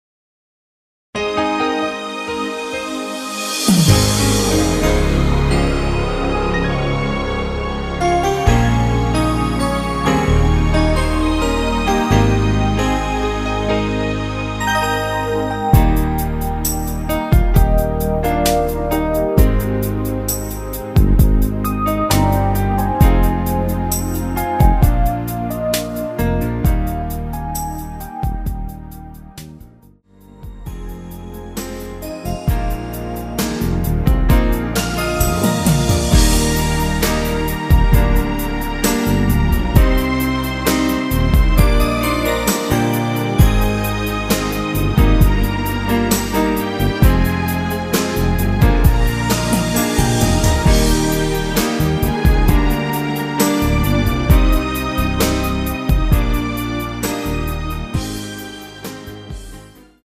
Eb
◈ 곡명 옆 (-1)은 반음 내림, (+1)은 반음 올림 입니다.
앞부분30초, 뒷부분30초씩 편집해서 올려 드리고 있습니다.
중간에 음이 끈어지고 다시 나오는 이유는